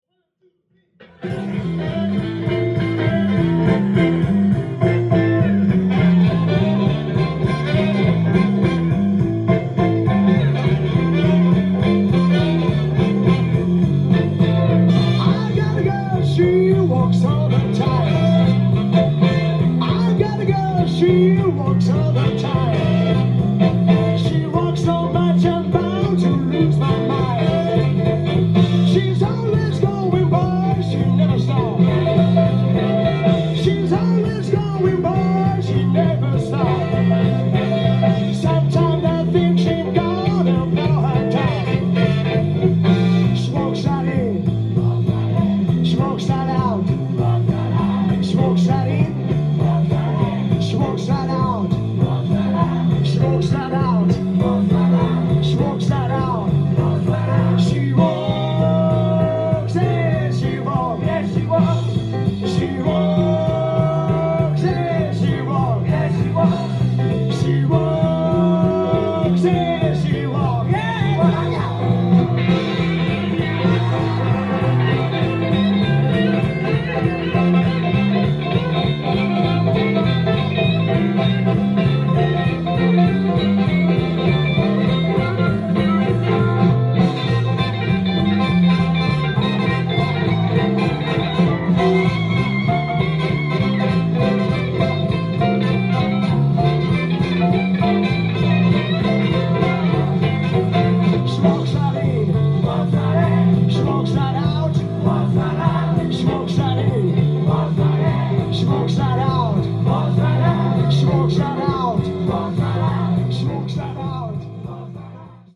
ジャンル：J-BLUES
店頭で録音した音源の為、多少の外部音や音質の悪さはございますが、サンプルとしてご視聴ください。